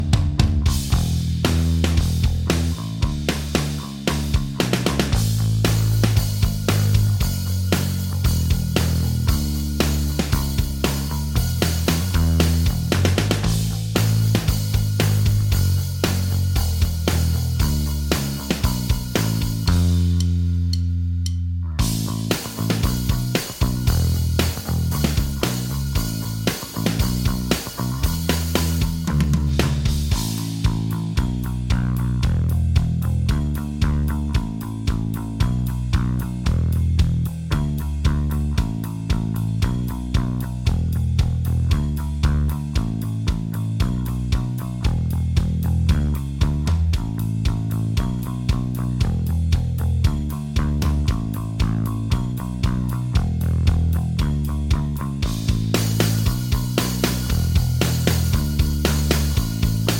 Indie / Alternative
Minus Main Guitars For Guitarists 3:45 Buy £1.50